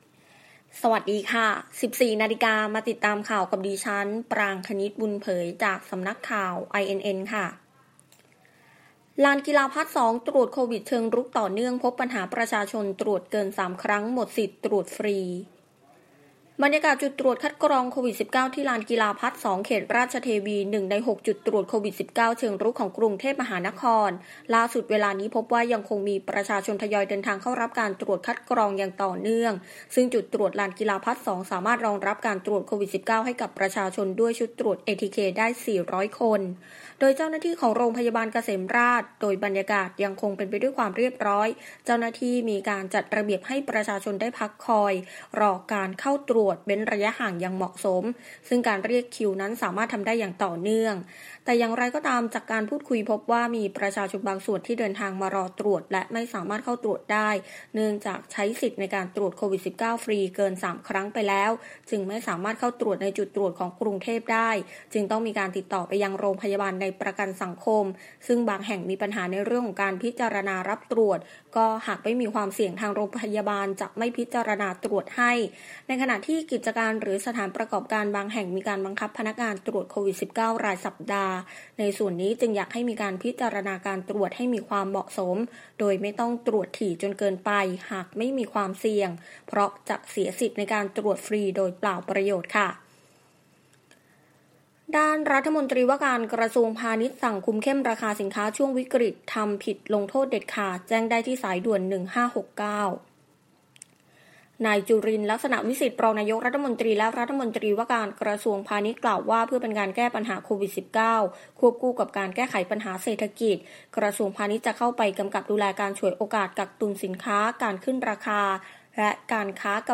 คลิปข่าวต้นชั่วโมง
ข่าวต้นชั่วโมง 14.00 น.